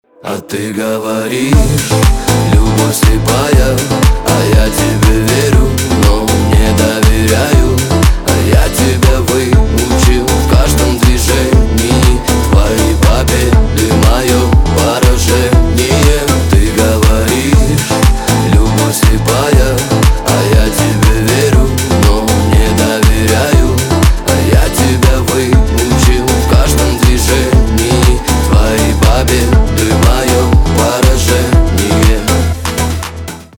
Грустная мелодия про расставание на телефон.